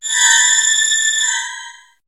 Cri de Sidérella dans Pokémon HOME.